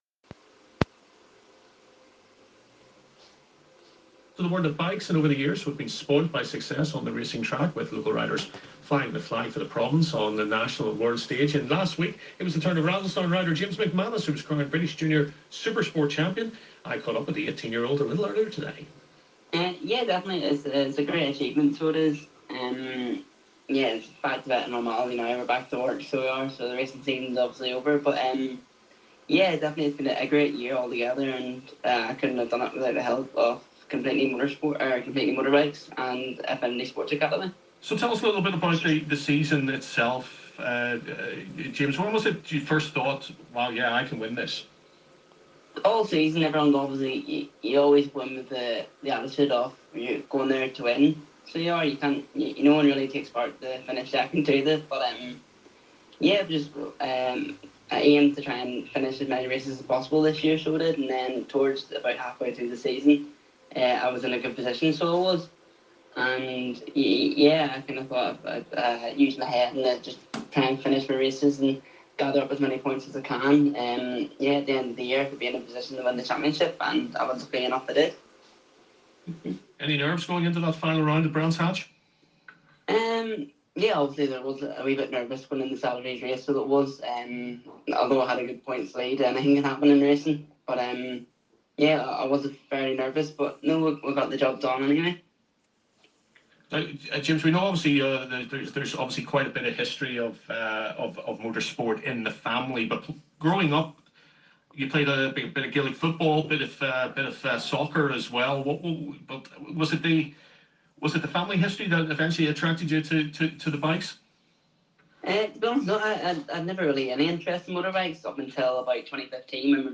BBC-Ulster-Interview.mp3